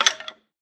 skeletonhurt.ogg